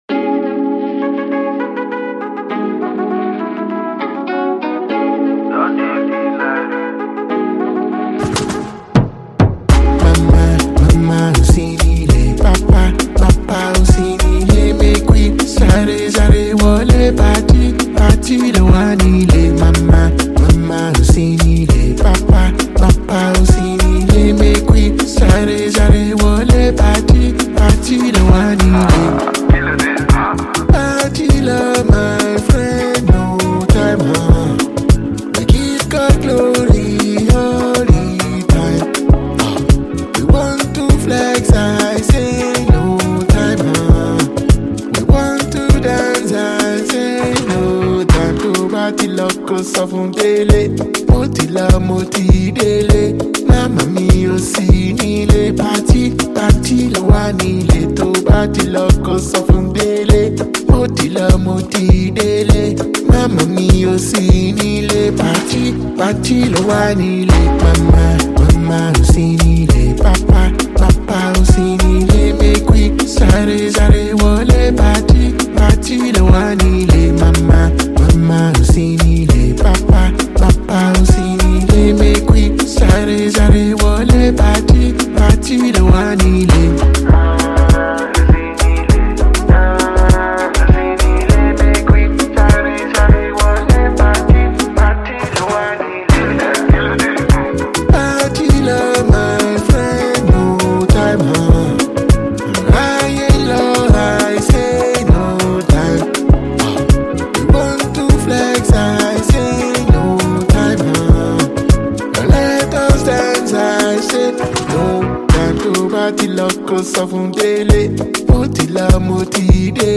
is an irresistible anthem with pumping beats
Afrobeats tune
The tune which captures a unique Afro sound